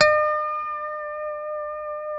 E5 PICKHRM2A.wav